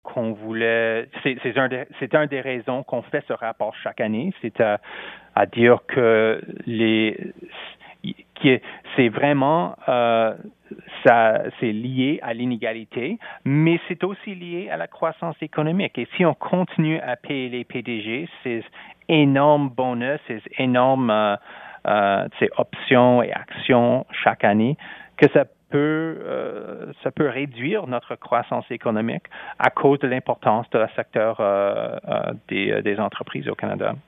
En entrevue avec Radio Canada International